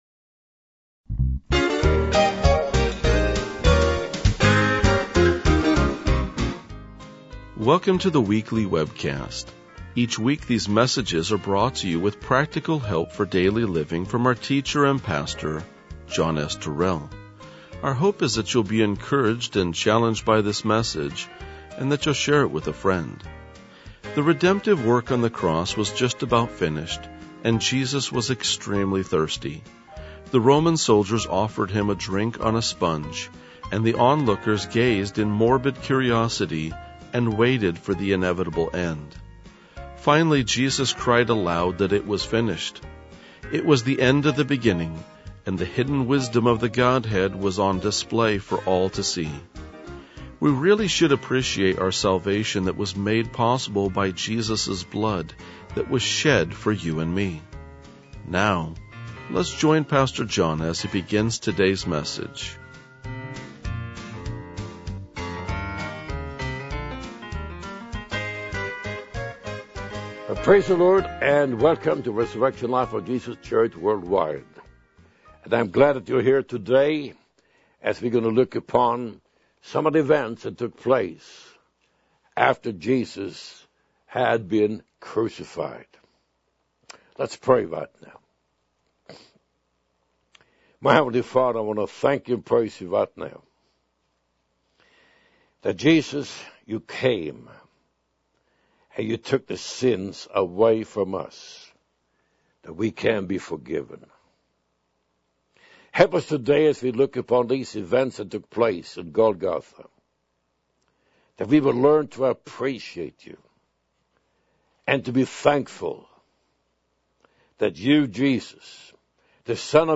RLJ-1986-Sermon.mp3